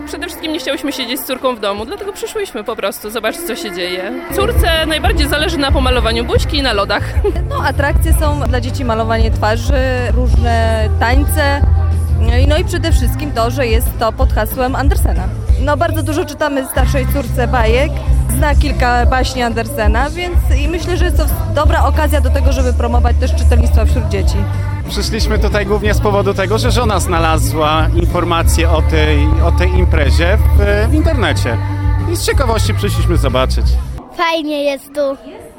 Od godziny 12 na deptaku przy Pomniku Bachusa trwają obchody Międzynarodowego Dnia Rodziny.
Na deptak przyszło wiele zainteresowanych rodzin z dziećmi: